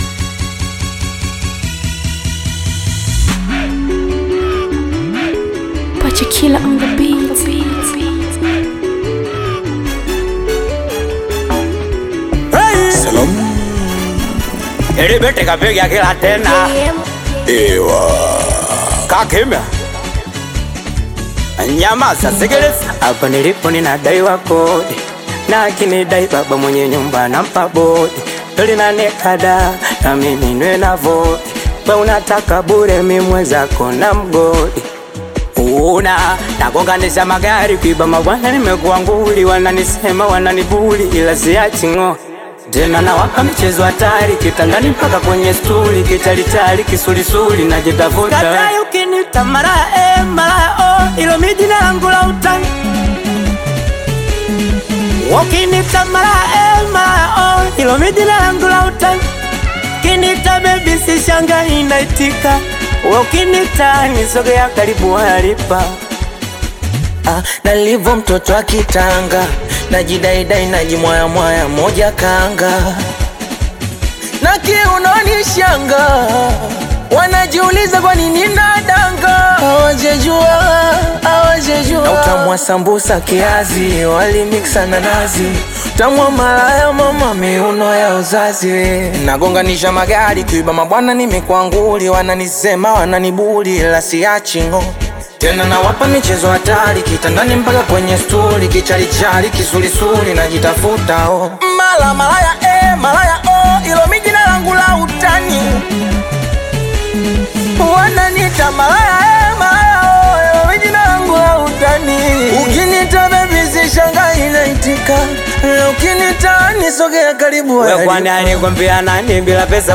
energetic Singeli/Afro-fusion single
Genre: Singeli